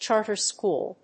音節chárter schòol